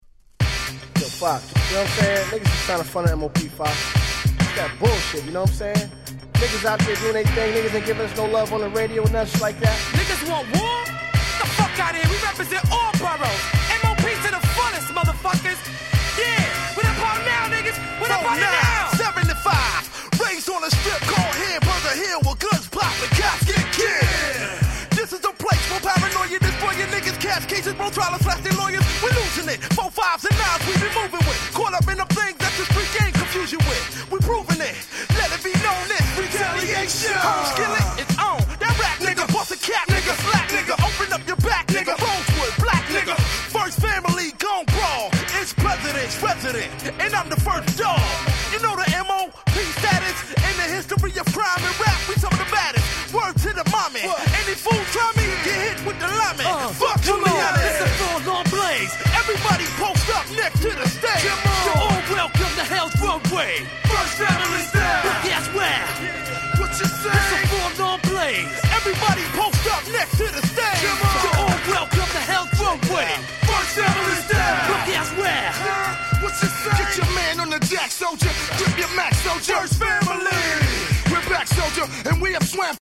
98' Smash Hit Hip Hop LP !!